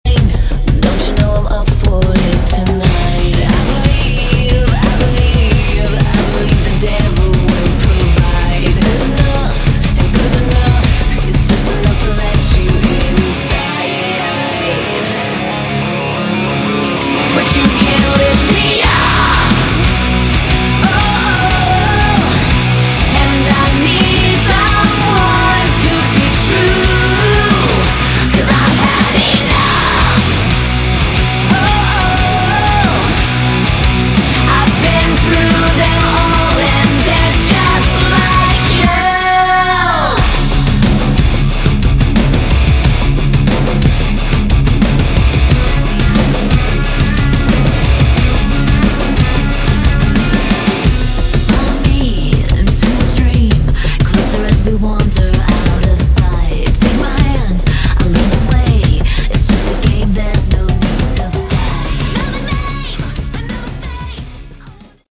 Category: Modern Hard Rock
guitars
drums